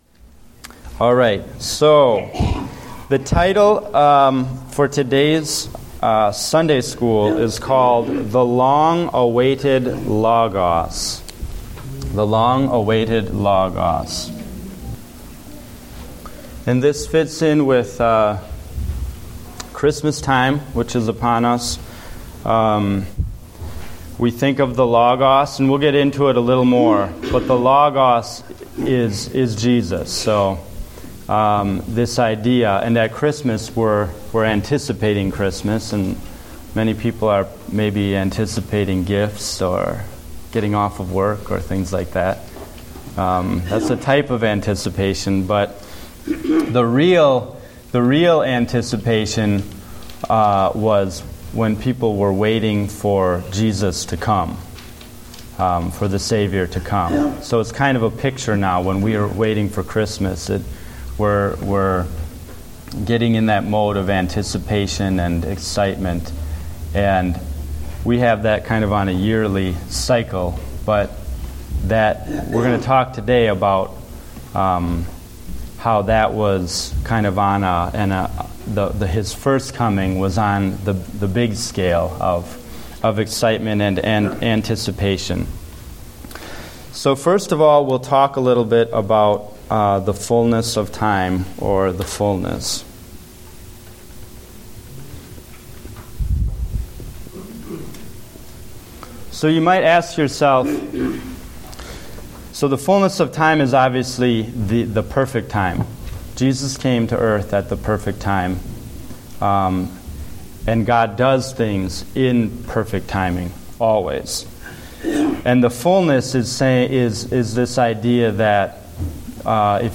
Date: December 14, 2014 (Adult Sunday School)